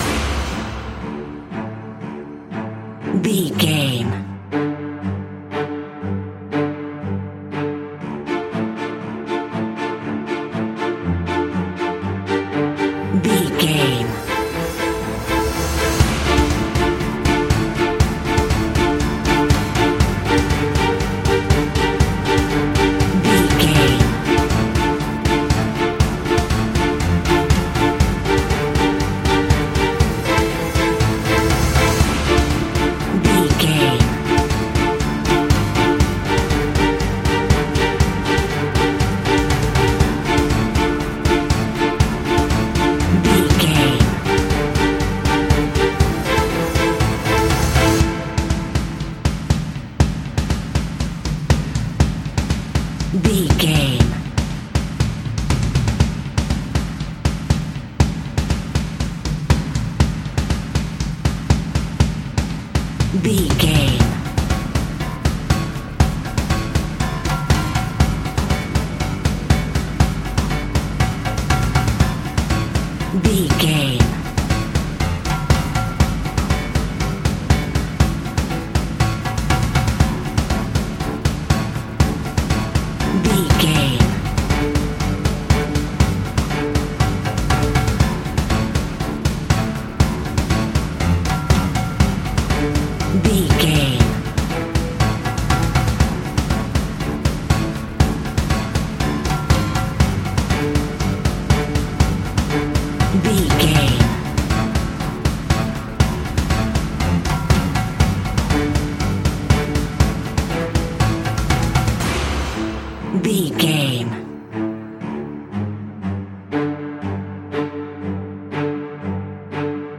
In-crescendo
Thriller
Aeolian/Minor
tension
ominous
eerie
horror music
Horror Pads
horror piano
Horror Synths